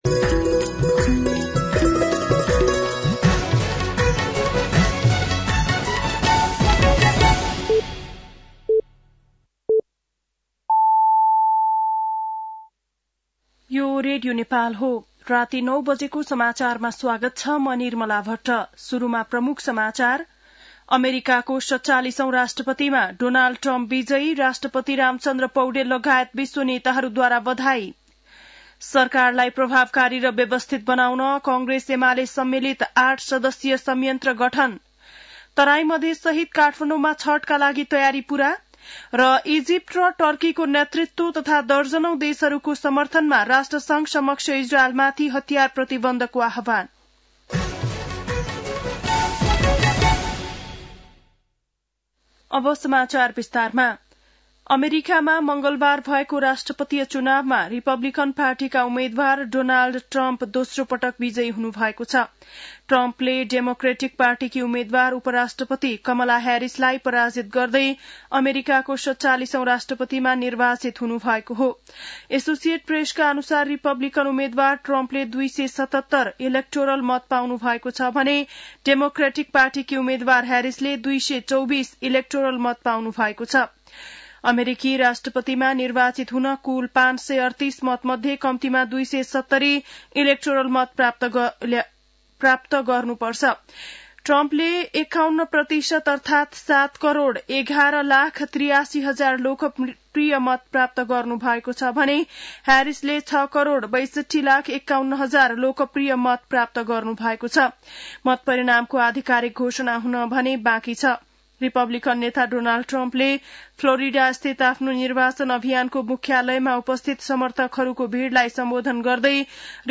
बेलुकी ९ बजेको नेपाली समाचार : २२ कार्तिक , २०८१
9-PM-Nepali-NEWS-7-21.mp3